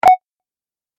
دانلود آهنگ کیبورد 16 از افکت صوتی اشیاء
دانلود صدای کیبورد 16 از ساعد نیوز با لینک مستقیم و کیفیت بالا
جلوه های صوتی